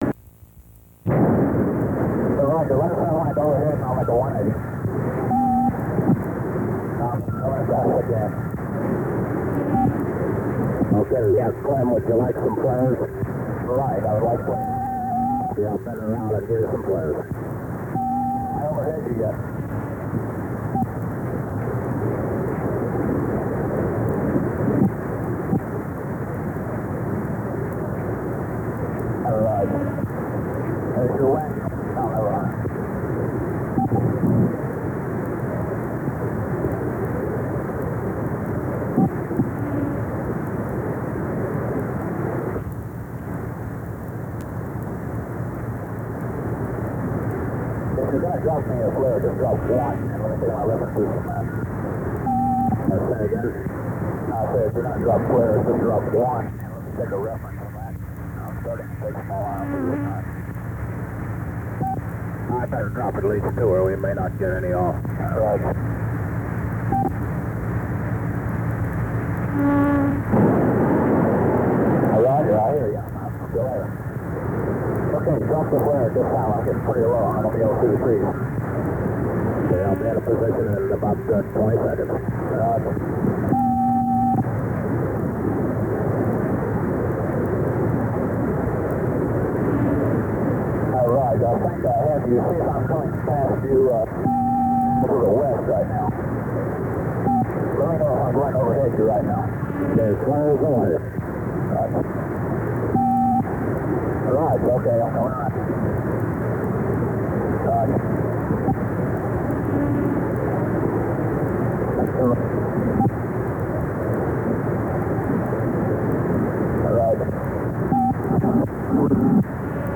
LT Clyde Lassen MOH HC-7 Helicopter Rescue Mission North Vietnam 19Jun68 Radio Audio-Click on the left end of the above bar to Listen
Lassen MOH Rescue Mission Live Audio Recording June 1968
Lassen.RescueAudio.mp3